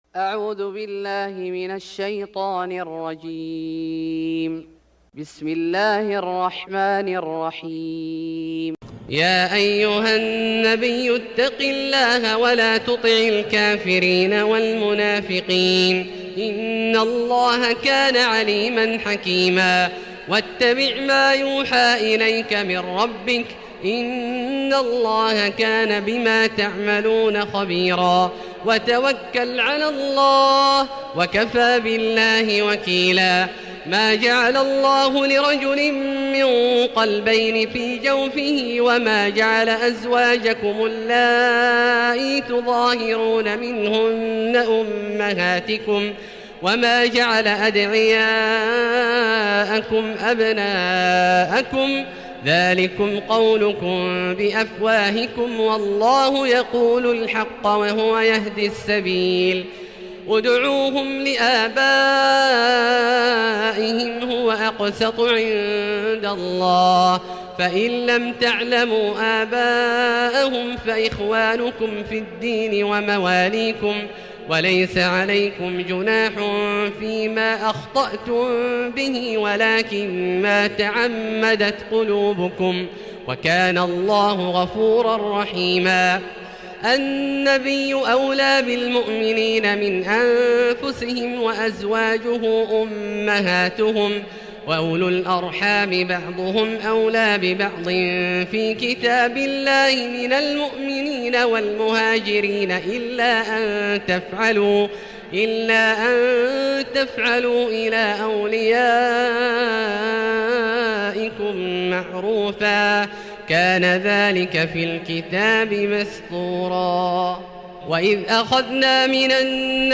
سورة الأحزاب Surat Al-Ahzab > مصحف الشيخ عبدالله الجهني من الحرم المكي > المصحف - تلاوات الحرمين